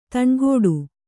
♪ taṇgōḍu